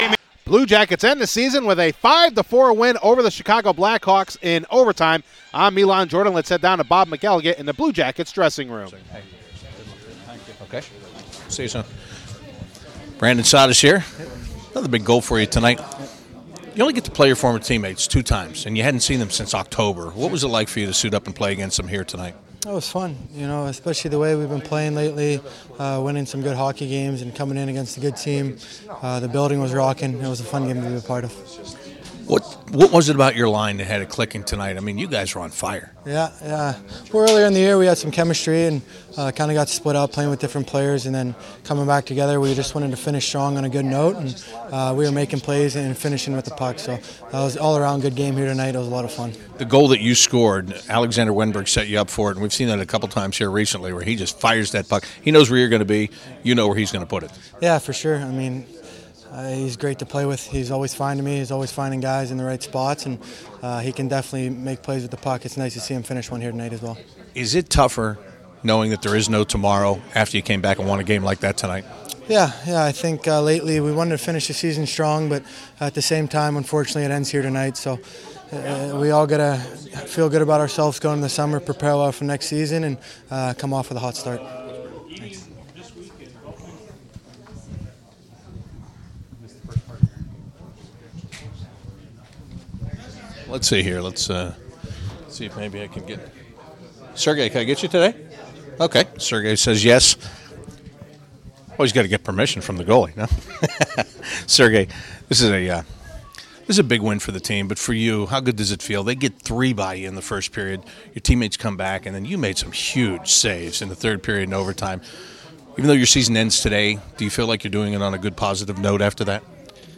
Blue Jackets in the locker after their final game of the 2015-2016 season